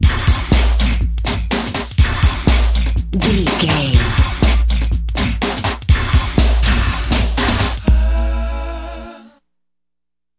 Ionian/Major
D
synthesiser
drum machine
electric guitar
drums
strings
Eurodance